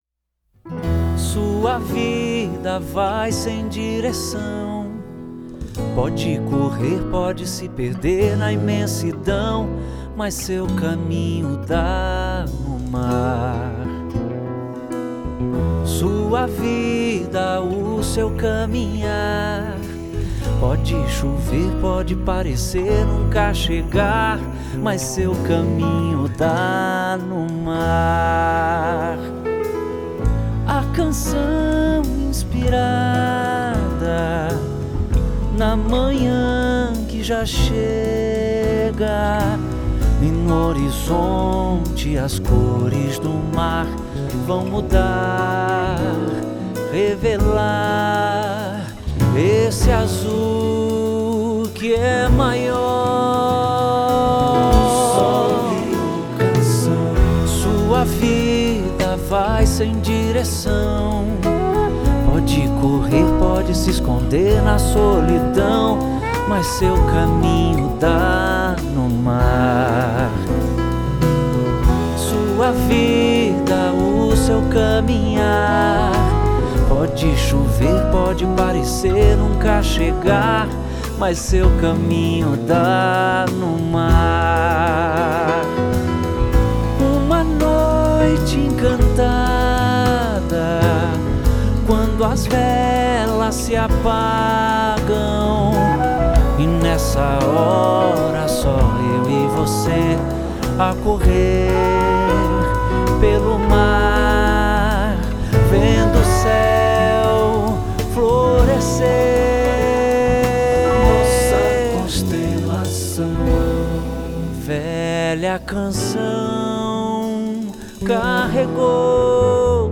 Bateria
Baixo
Percussão
Sax Alto
Backing vocal